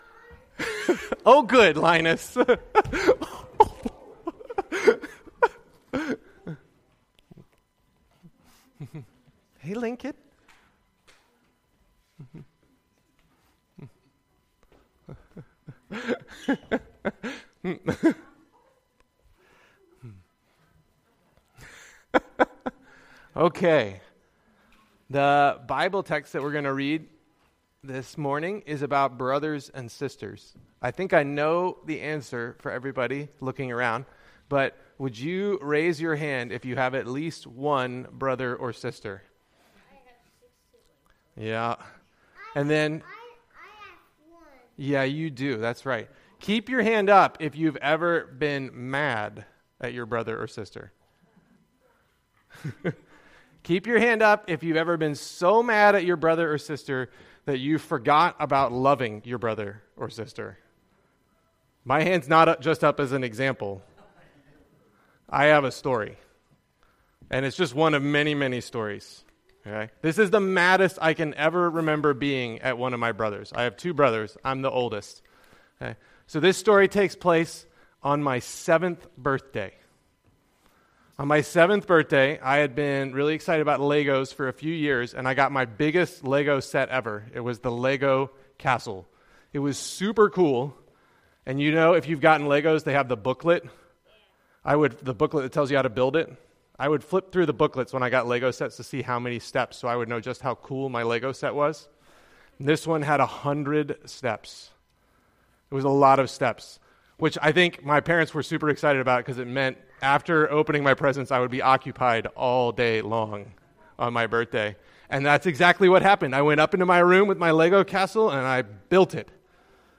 Children’s Sermon